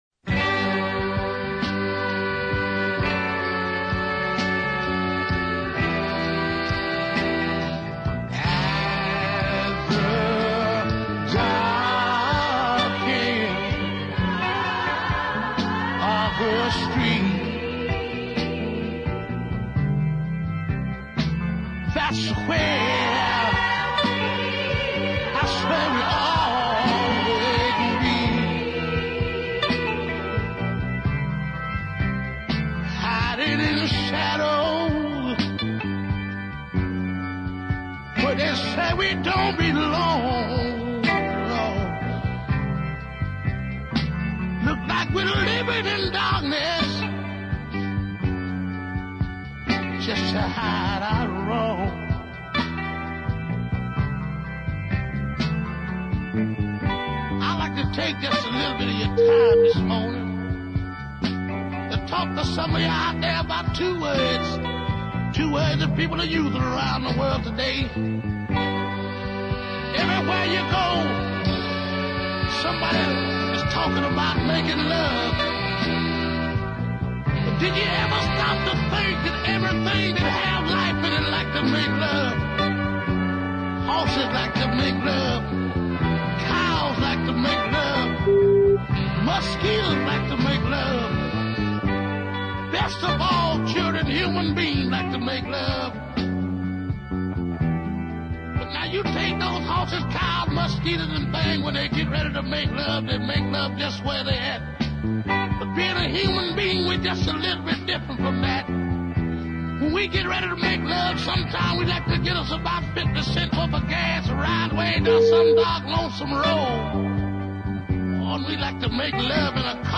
deep soul music